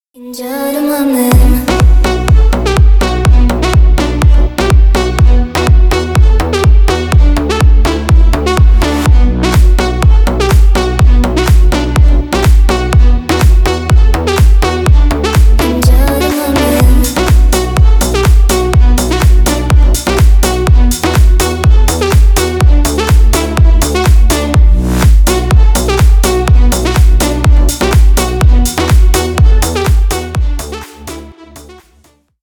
• Качество: 320 kbps, Stereo
Поп Музыка
клубные